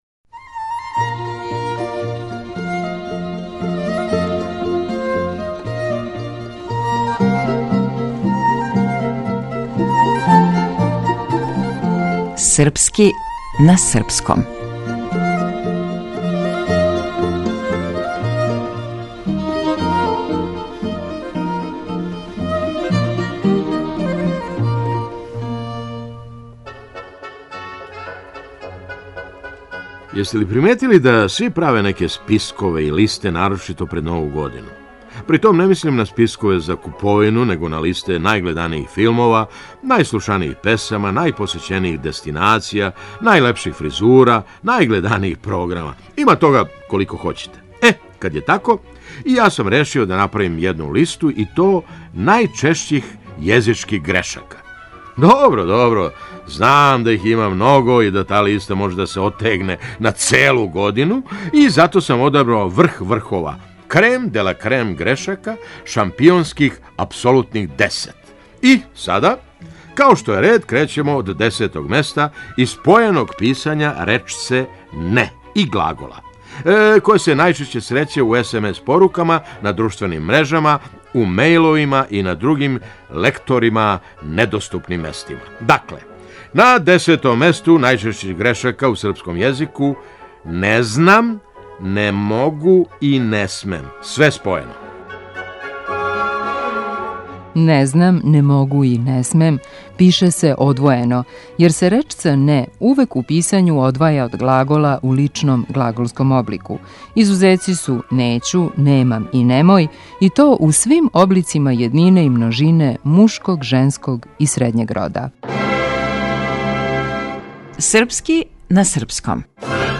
Глумац - Феђа Стојановић